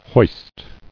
[hoist]